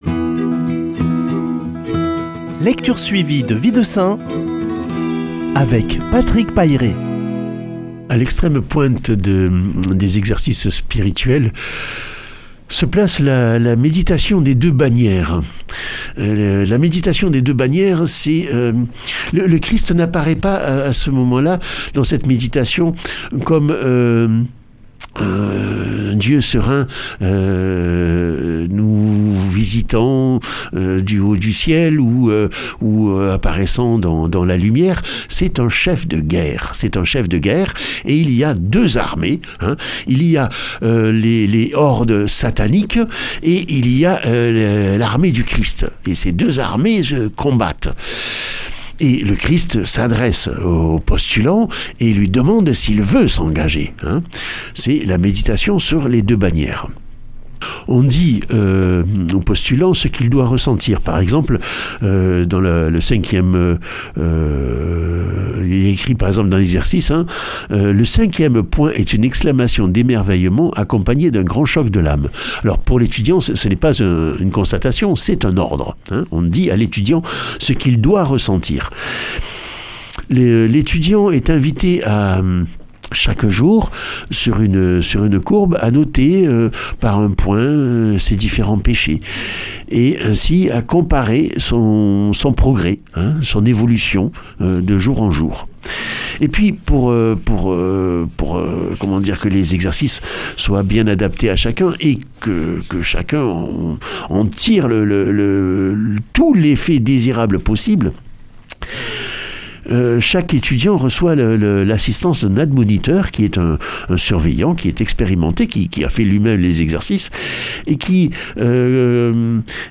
vendredi 17 avril 2020 Récit de vie de saints Durée 2 min
Lecture suivie de la vie des saints